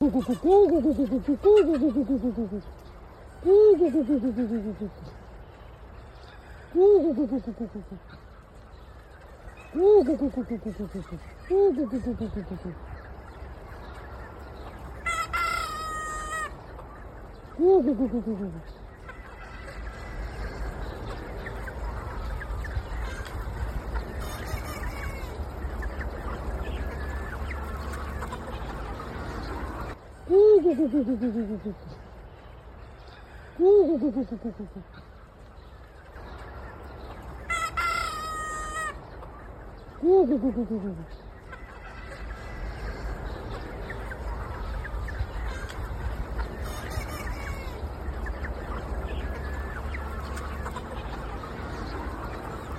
Tiếng Cu gà, Cục cục cho gà ăn, Gọi gà đến… ở trang trại nuôi gà
Thể loại: Tiếng con người
Description: Trang trại gia cầm nhiều con gà thả, tiếng cu cu cu gà, cục cu gà của người nuôi, gọi gà đến thường nghe thấy khi nuôi gà ở nông thôn, làng quê, chăn nuôi, tiếng đàn gà chen chúc quanh máng ăn, nháo nhác đập cánh và kêu cục tác, cục cục, ò ó o... rộn ràng.
tieng-cu-ga-goi-ga-den-o-trang-trai-nuoi-ga-www_tiengdong_com.mp3